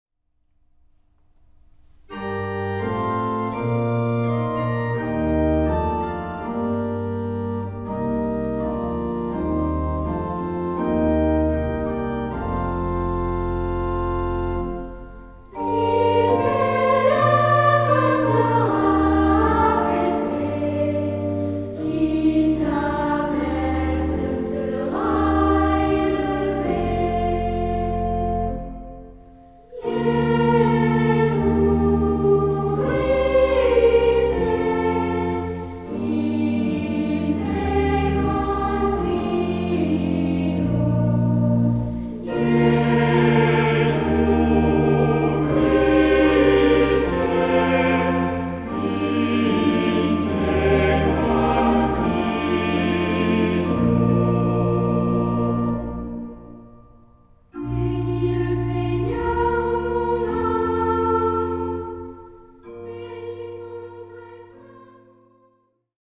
Genre-Stil-Form: Tropar ; Psalmodie
Charakter des Stückes: andächtig
Chorgattung: SAH ODER SATB  (4 gemischter Chor Stimmen )
Instrumente: Orgel (1)
Tonart(en): B-dur